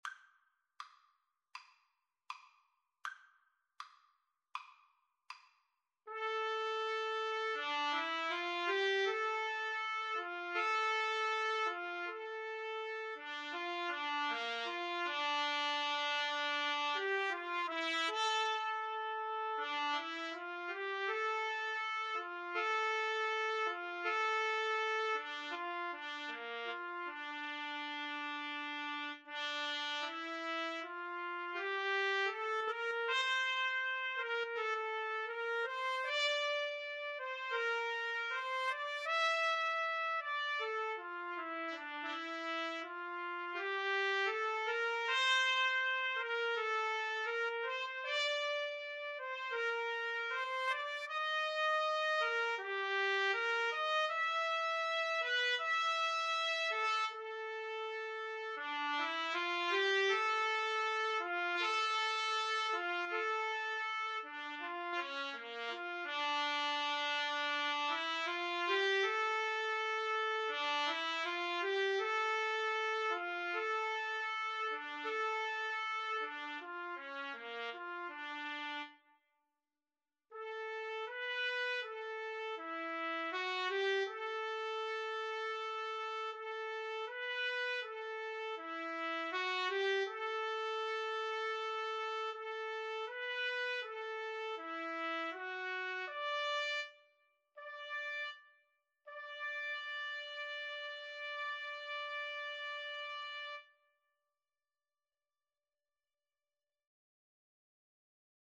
Andante = 80
Classical (View more Classical Trumpet-Trombone Duet Music)